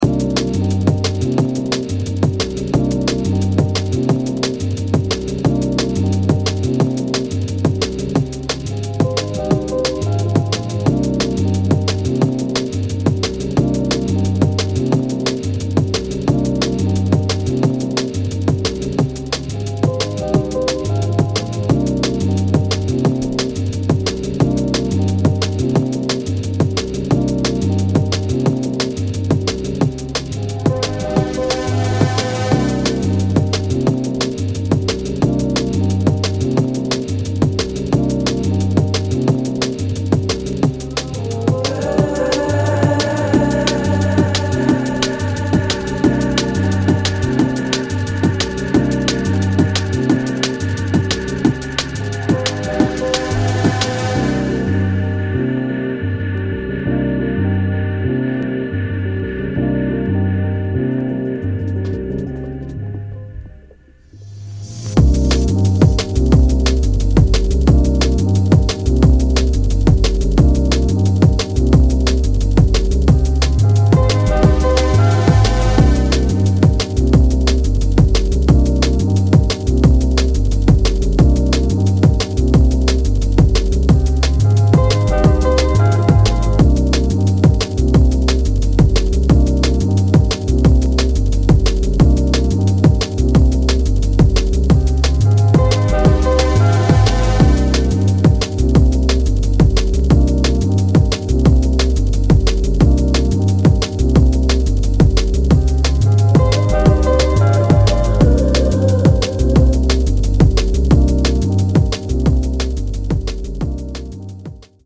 D&B